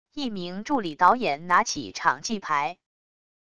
一名助理导演拿起场记牌wav音频